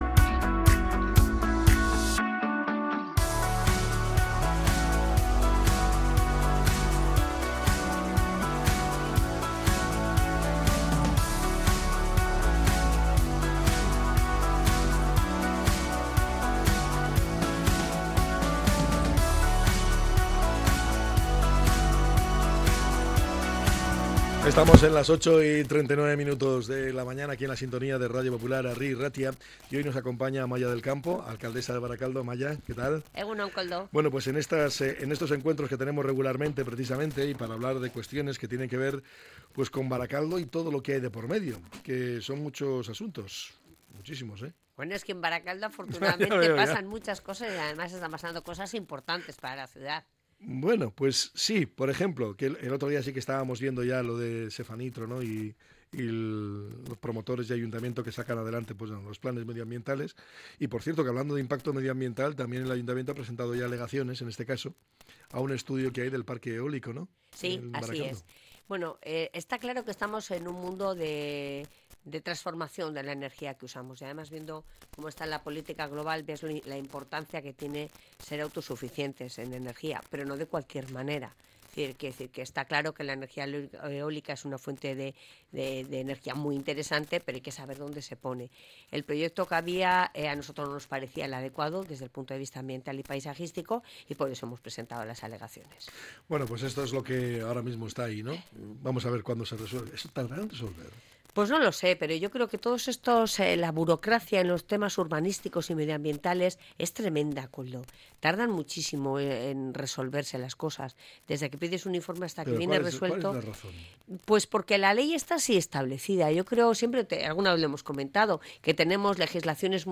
La alcaldesa, Amaia del Campo, ha repasado la actualidad del municipio